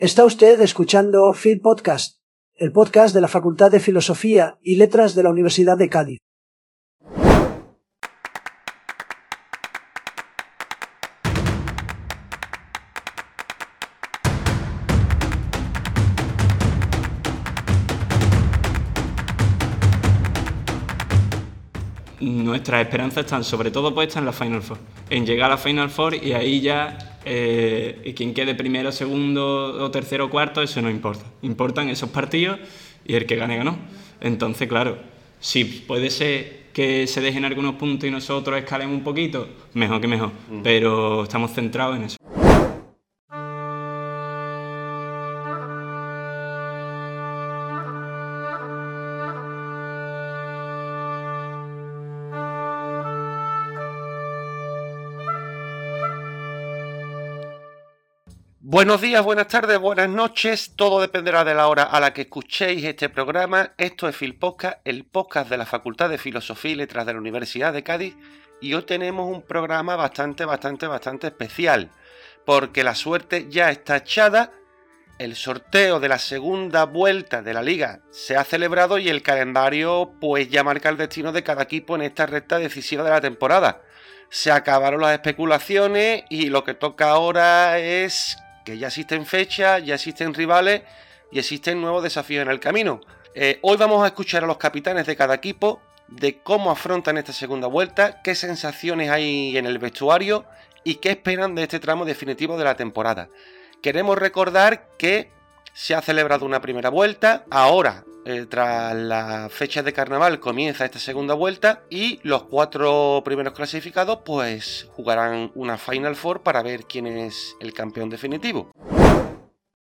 En este capítulo la protagonista central en la II Liga de Futsal de la Facultad. Los capitanes de cada equipo y otras personas implicadas en el desarrollo del torneo nos trasladan sus impresiones sobre la primera vuelta.